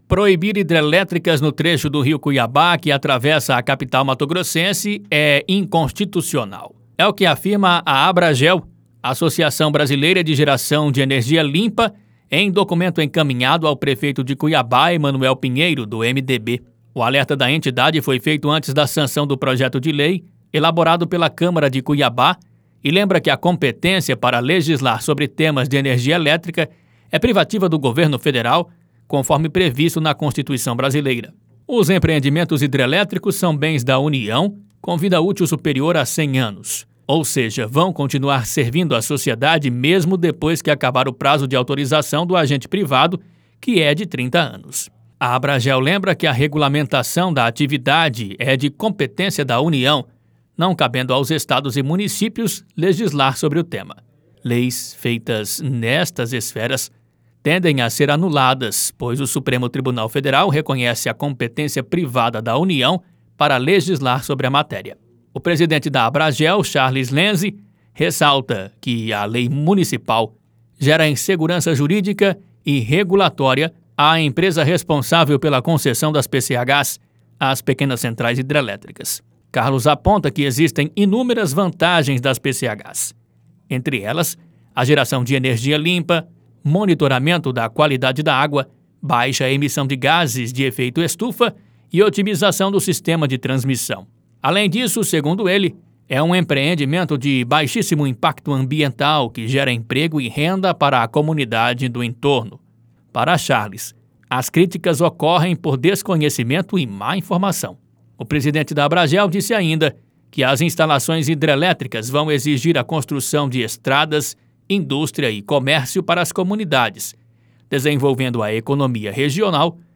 Boletins de MT 15 fev, 2022